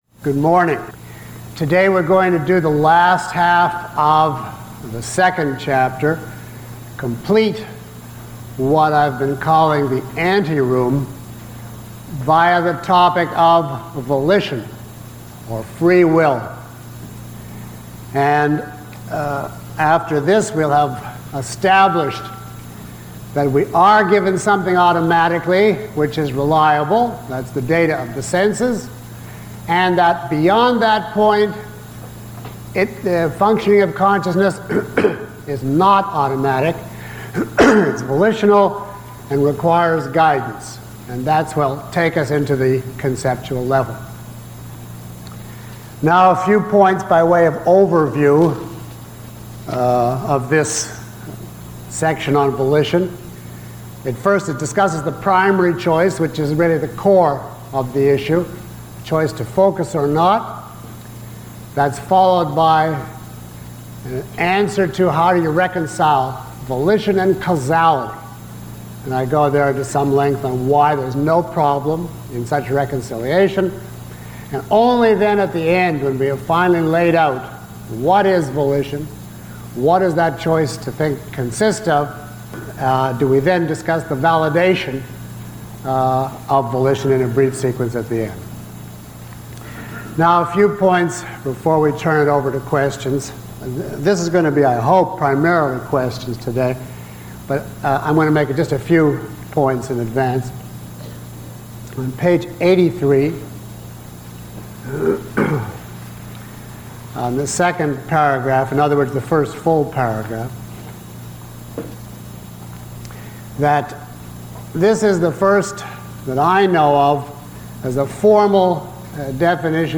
Q&A Guide Below is a list of questions from the audience taken from this lecture, along with (approximate) time stamps. 45:10 A written question: When and by what means does a child become aware of his consciousness and volition, i.e., know that the axioms of consciousness and volition are true?